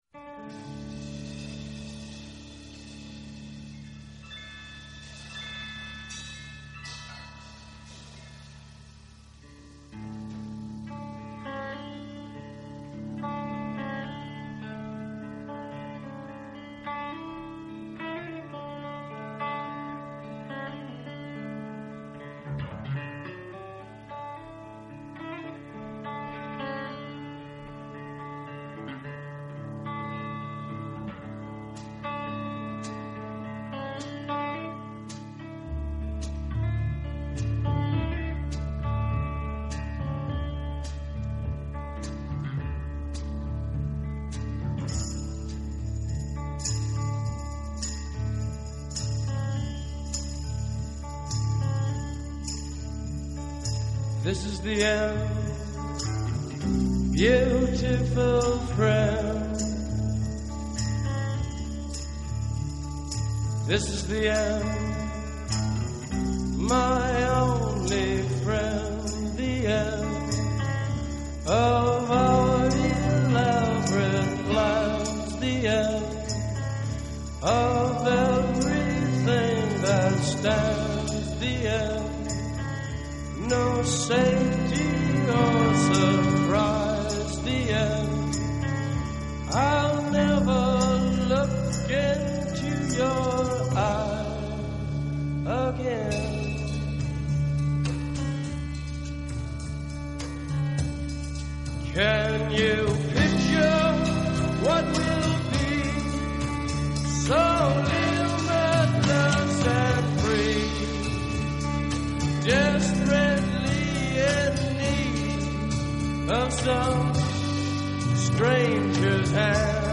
TV концерт